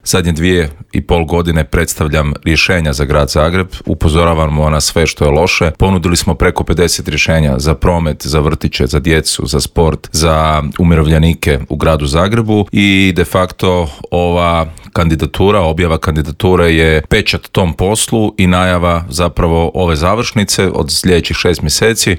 Zagreb već zna nekoliko kandidata za gradonačelnika, a svoju kandidaturu objavio je i nezavisni kandidat Davor Bernardić koji je u Intervjuu Media servisa poručio: "Imamo rješenje za prometne probleme, a Tomaševićevi najavljeni projekti su samo prazno predizborno obećanje. Nema šanse da Maksimir bude gotov do 2028. kao ni Centar za gospodarenje otpadom."